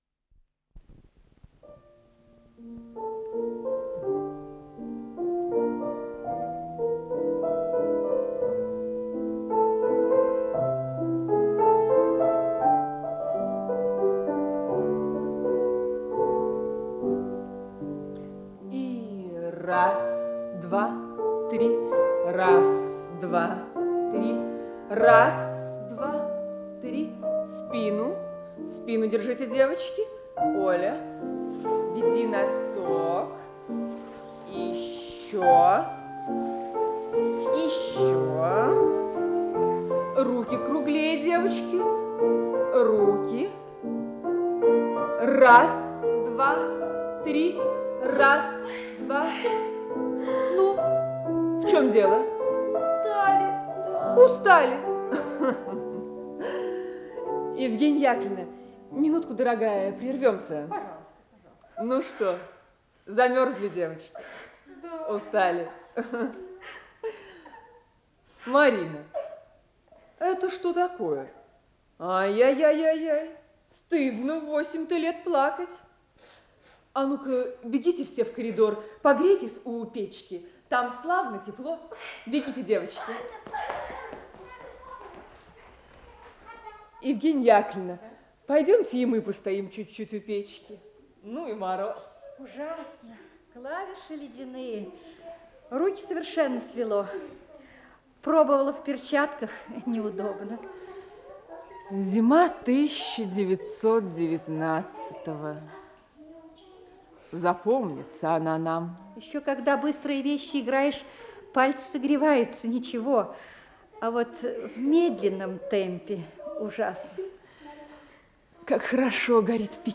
Исполнитель: Артисты московских театров
Радиоспектакль